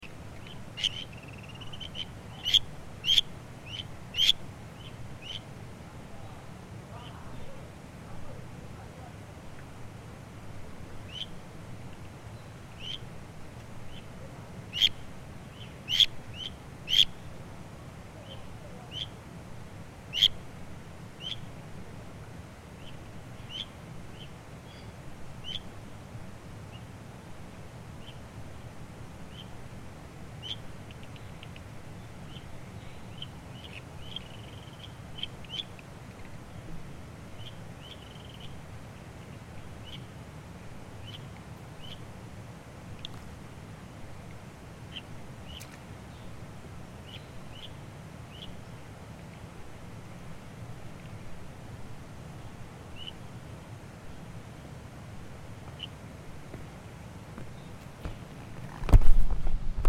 日本樹蛙 Buergeria japonica
花蓮縣 秀林鄉 沙卡礑水域四
錄音環境 溪流旁
行為描述 5隻以上競叫